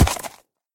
Sound / Minecraft / mob / horse / land.ogg
land.ogg